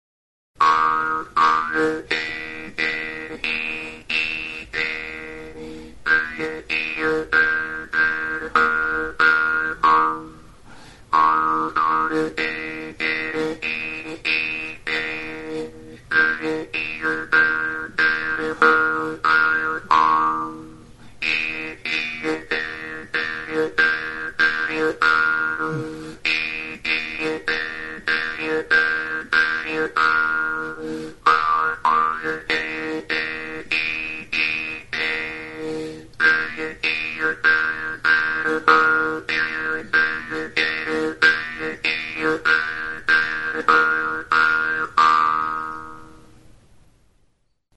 MAULTROMMELN; JEW'S HARP | Soinuenea Herri Musikaren Txokoa
Enregistr� avec cet instrument de musique.
Altzairuzko mihi luzea du erdi-erdian, hatzarekin astintzerakoan libre bibratzen duena.